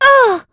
Estelle-Vox_Damage_04.wav